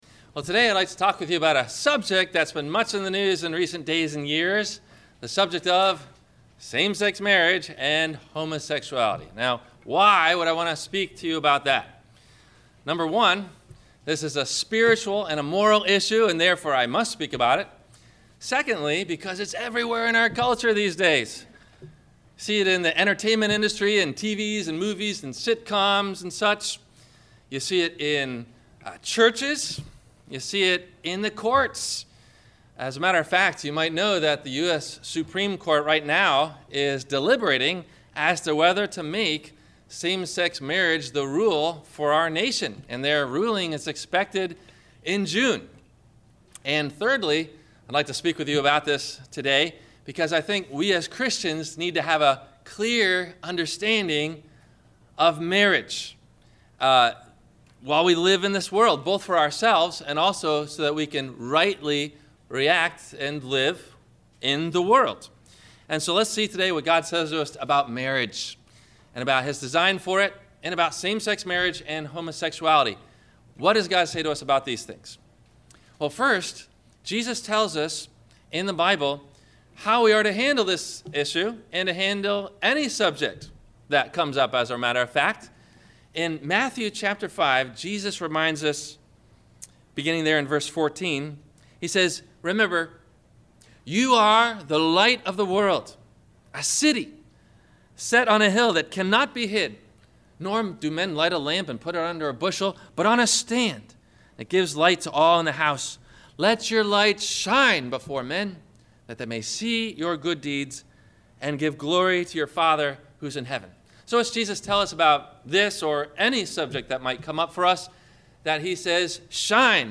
The Lord’s Supper (Holy Communion) – WMIE Radio Sermon – June 22 2015